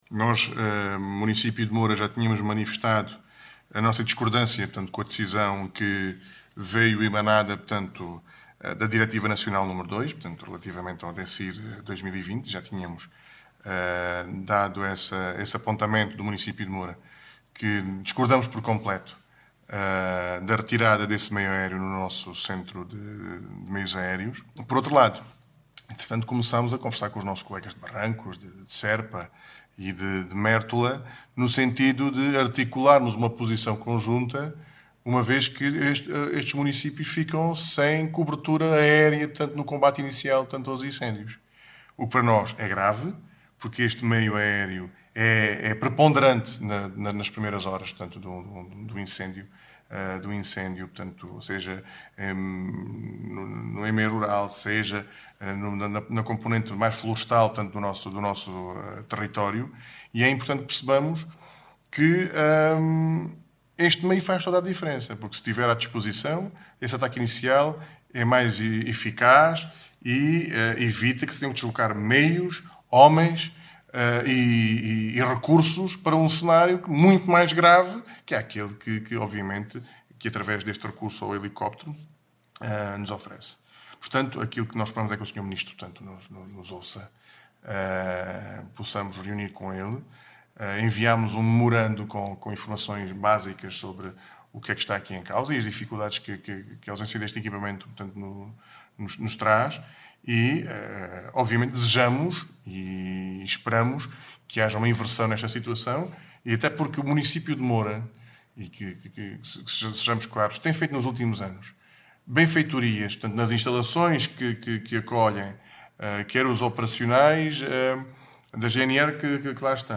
Declarações-Presidente-Álvaro-Azedo.mp3